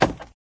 woodwood.ogg